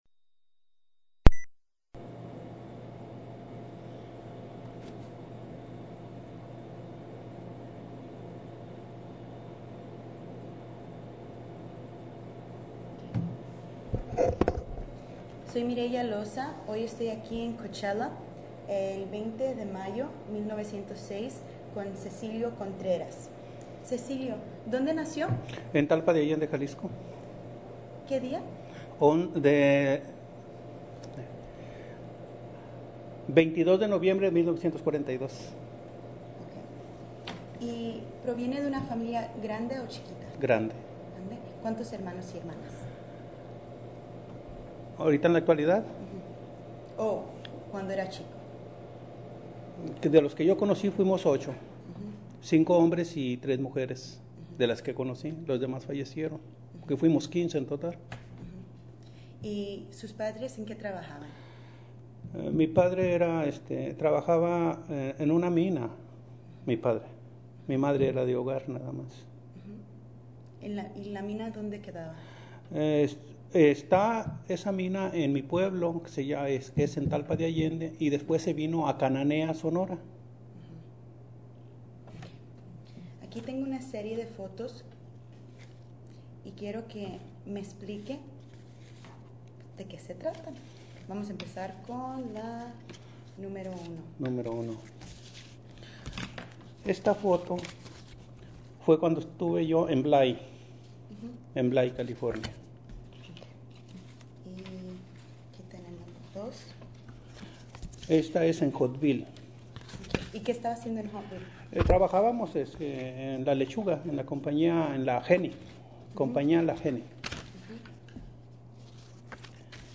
Original Format Mini disc
Location Coachella, CA